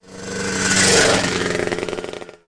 PAGE_CLOSE.mp3